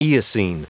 Prononciation du mot eocene en anglais (fichier audio)
Prononciation du mot : eocene